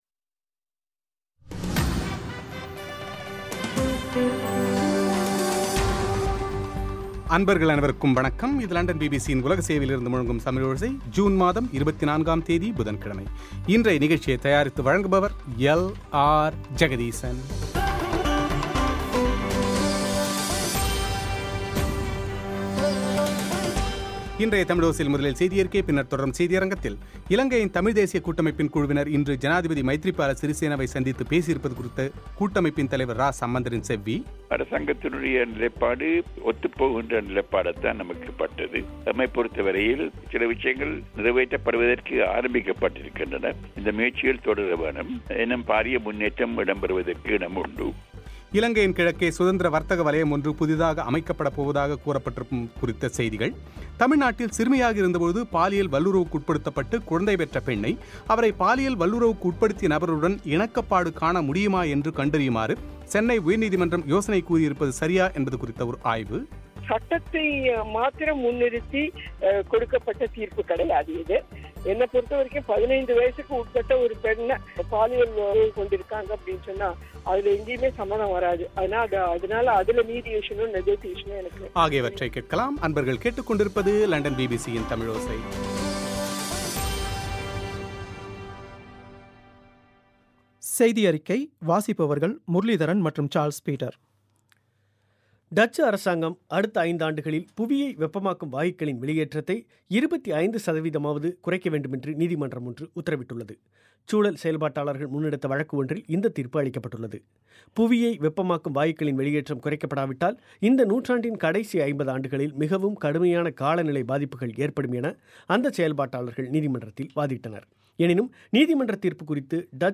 இலங்கையின் தமிழ்த் தேசியக் கூட்டமைப்பின் குழுவினர் இன்று ஜனாதிபதி மைத்திரிபால சிறிசேனவை சந்தித்து பேசியுள்ளது குறித்து கூட்டமைப்பின் தலைவர் இரா சம்பந்தரின் செவ்வி;